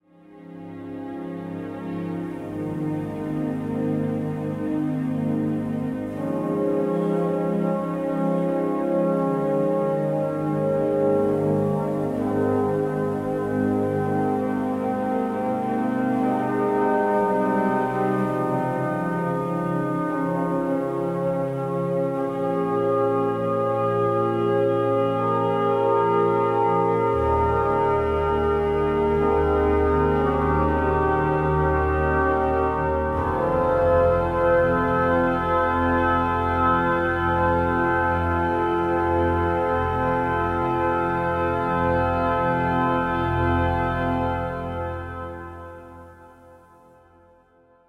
Entspannungsmusik
Instrumentalmusik
Meditationsmusik
Sanfte Melodien